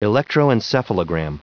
Prononciation du mot electroencephalogram en anglais (fichier audio)
Prononciation du mot : electroencephalogram
electroencephalogram.wav